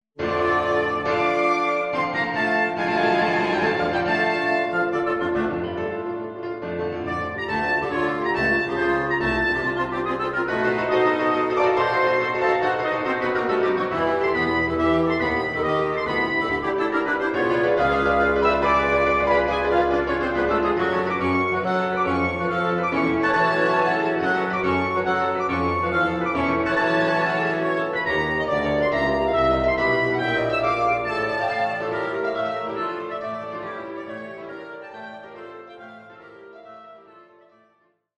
Arranged for Piano, Oboe, Clarinet in Bb & Bassoon.